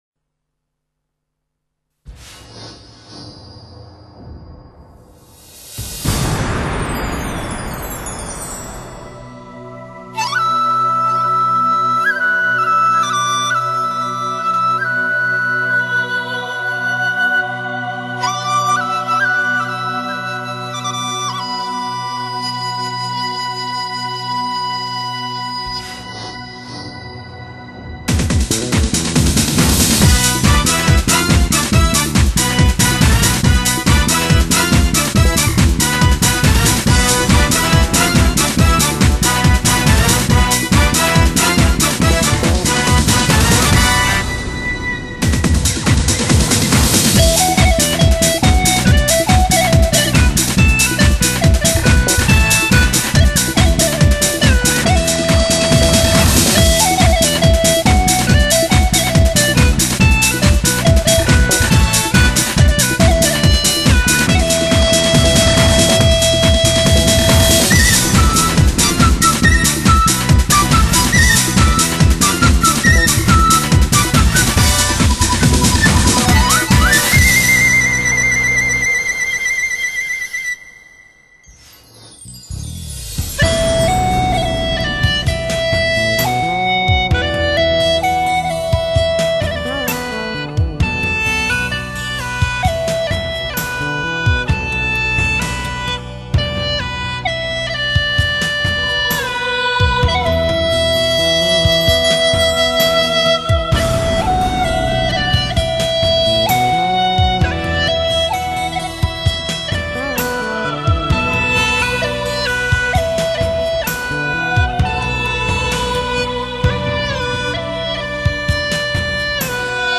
巴乌与葫芦丝为云南少数民族乐器。
其音色轻柔细腻，圆润质朴，极富表现力，深受云南人民喜爱。
它独特的音色听起来极具新鲜感，带有浓郁醇厚的乡土气息。
两者所演绎的曲目也极尽轻柔幻美感受，
版本录音极为精细，是当下市场所见最优一款，